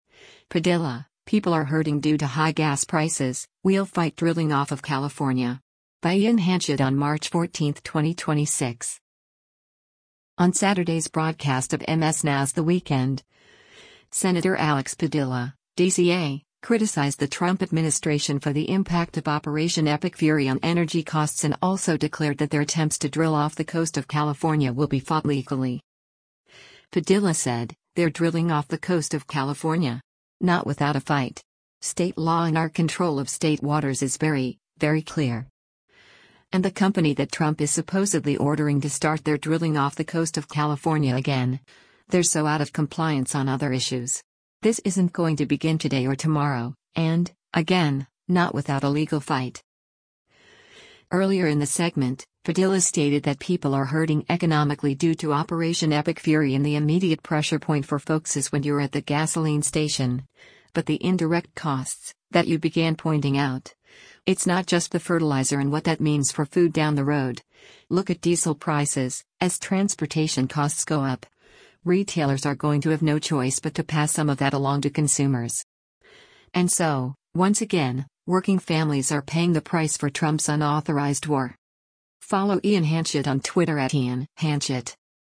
On Saturday’s broadcast of MS NOW’s “The Weekend,” Sen. Alex Padilla (D-CA) criticized the Trump administration for the impact of Operation Epic Fury on energy costs and also declared that their attempts to drill off the coast of California will be fought legally.